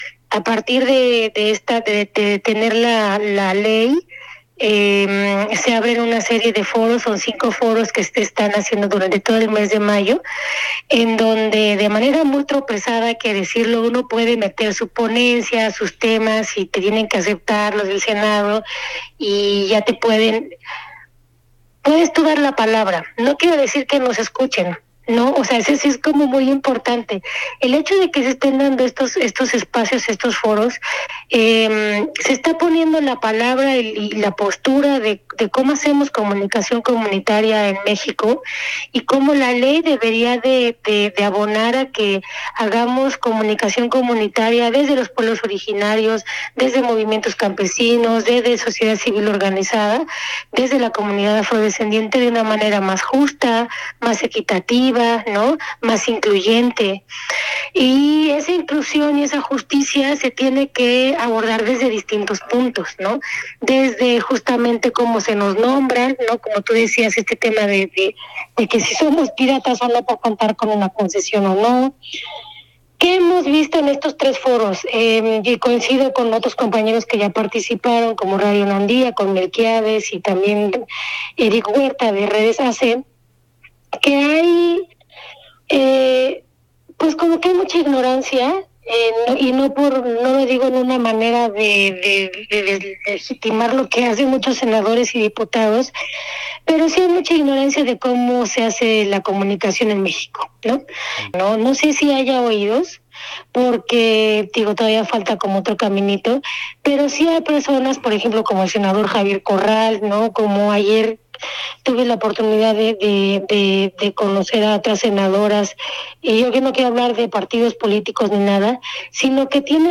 Platicamos